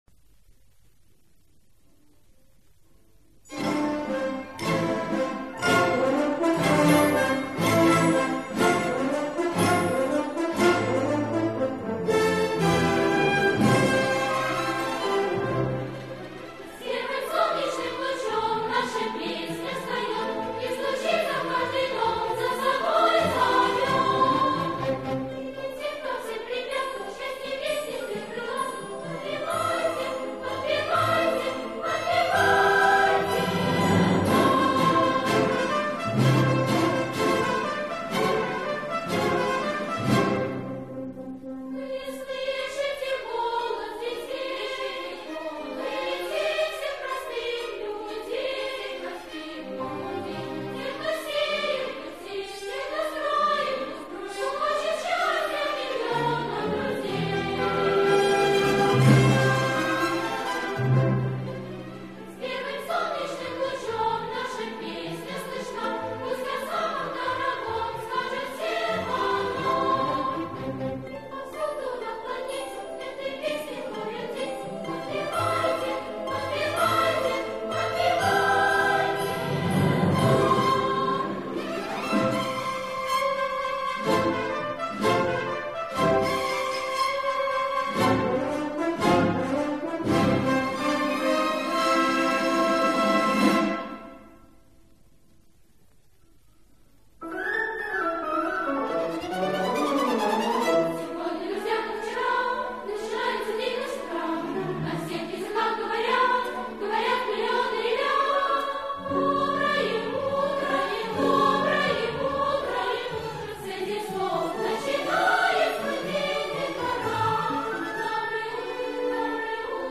Светлая весення фантазия
Солистки